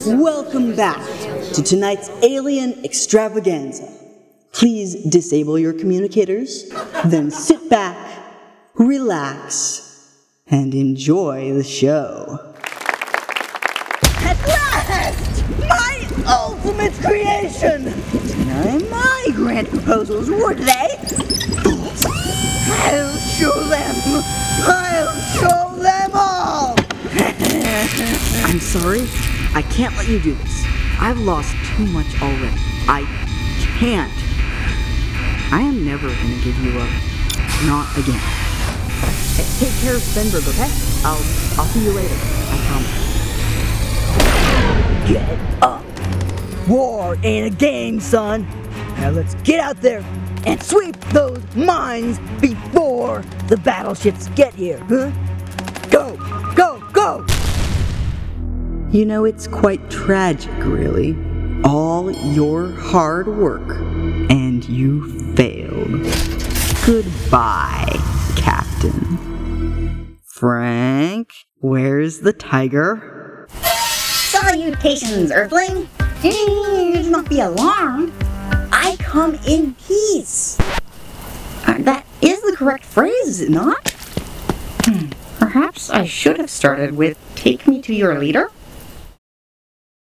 Voice actor extraordinaire
Character Demo